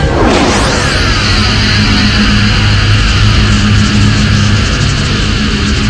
Pictures: Generatore deflettore Deflettore Attivazione scudo Resistenza scudi Media: Rumore deflettore References:
deflettore.wav